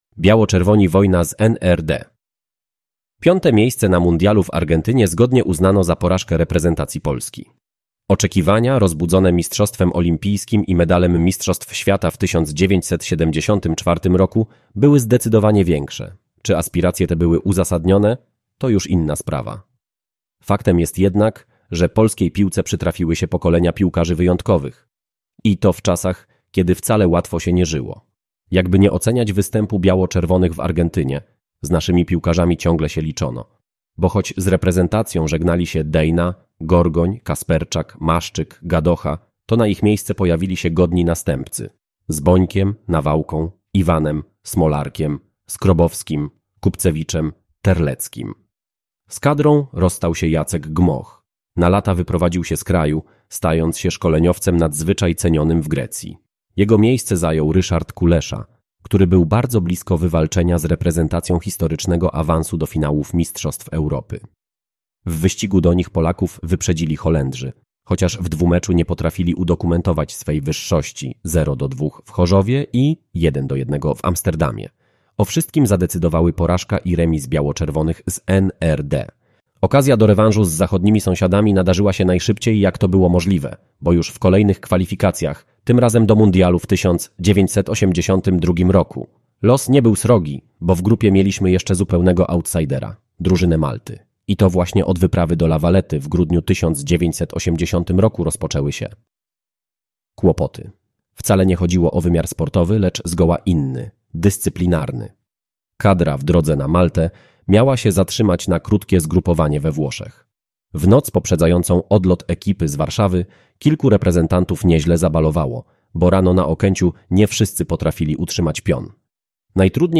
Audiobook 1982 Hiszpania. Mundial Historia, Jerzy Cierpiatka, Marek Latasiewicz, Mirosław Nowak.